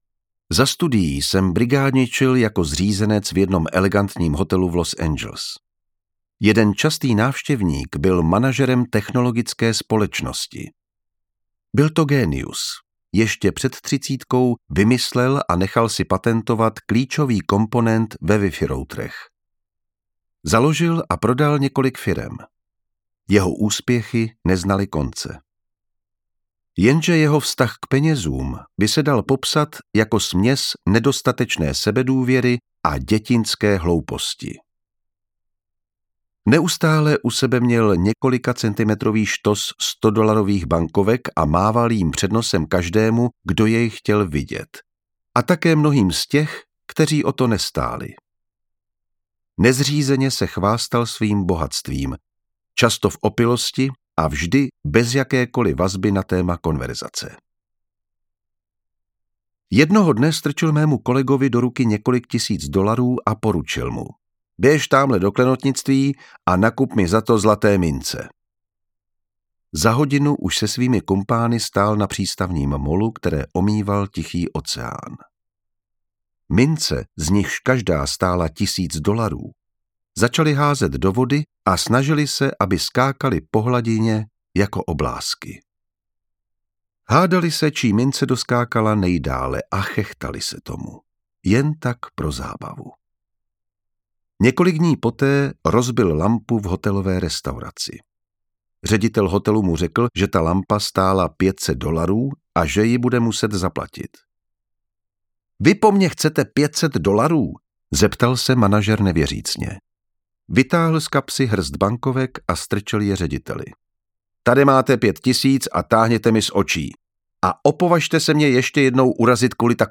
Psychologie peněz audiokniha
Ukázka z knihy
• InterpretJan Vondráček